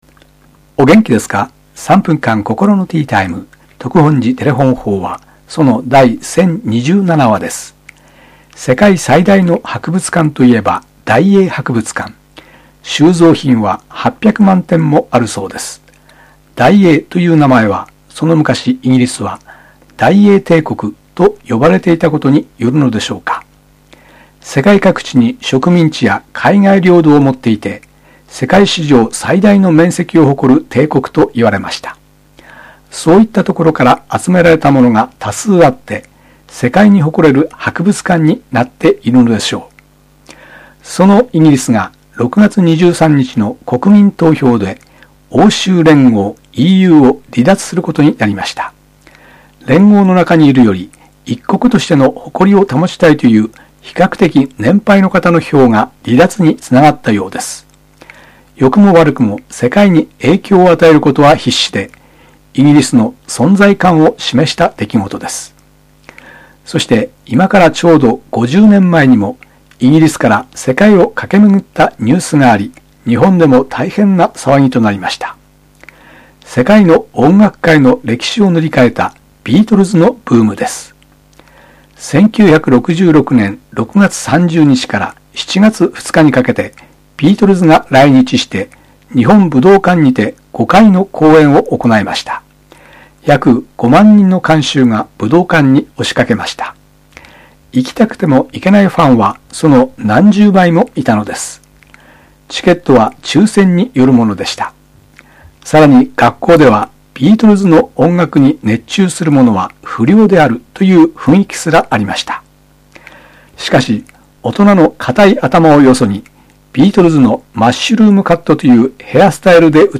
テレホン法話